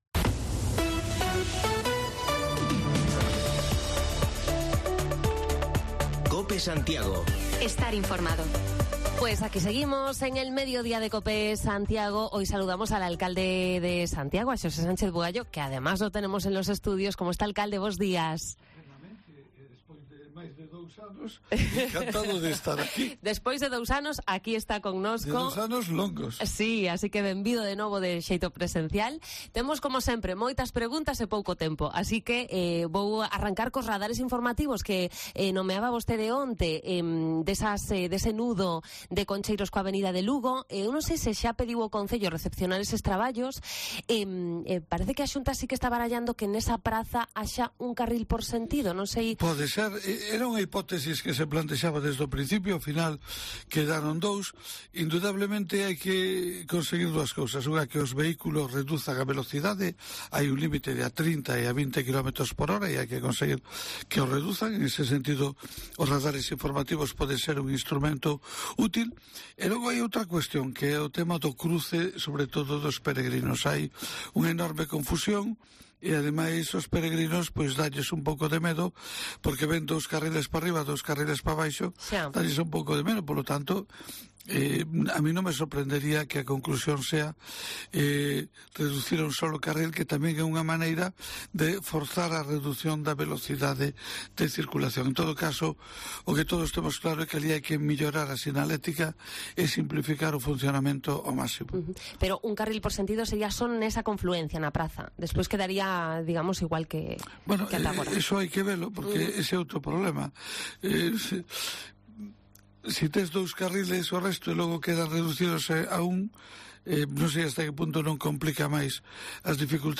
Repasamos la actualidad municipal en entrevista con el alcalde de Santiago, Xosé Sánchez Bugallo